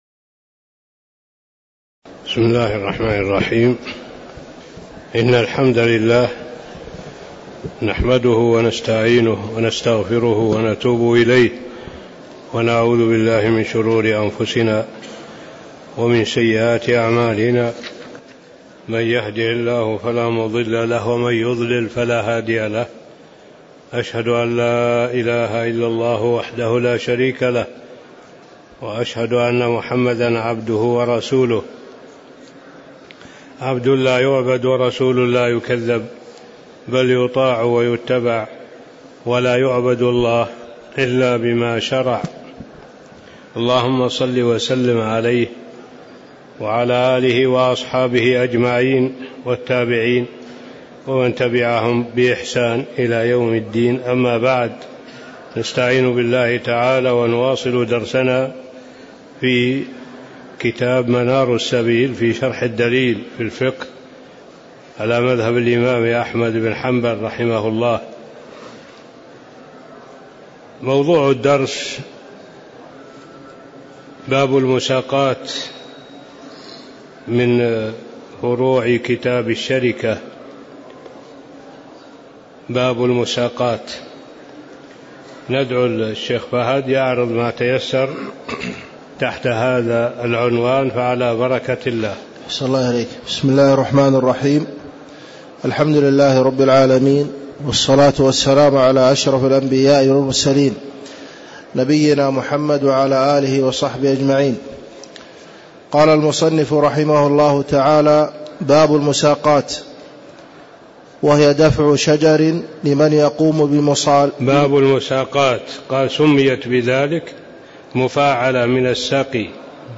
تاريخ النشر ٩ صفر ١٤٣٧ هـ المكان: المسجد النبوي الشيخ